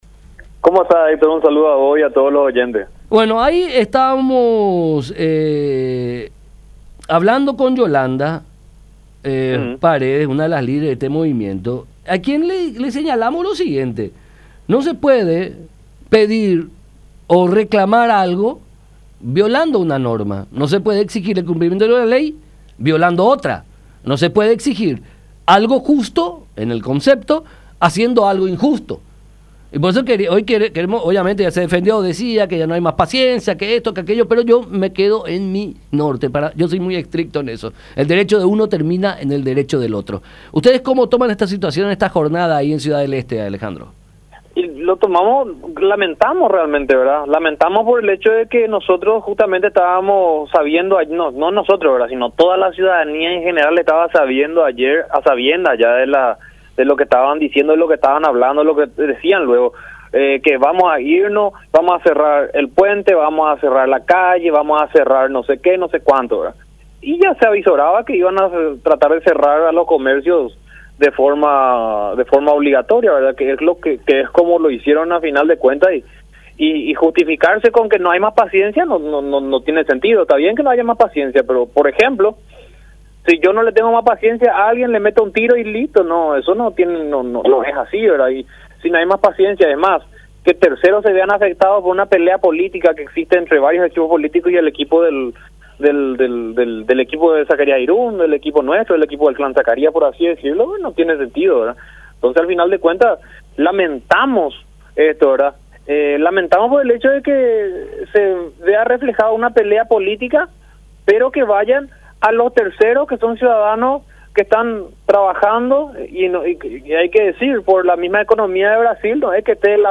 “Se avizoraba que iban a cerrar comercios, que iban a coartar a mucha gente”, aseveró el edil oficialista en comunicación con La Unión, añadiendo que comercios que se negaban a cerrar sus locales fueron atacados por una turba, con el objetivo de obligarlos a sumarse a la manifestación.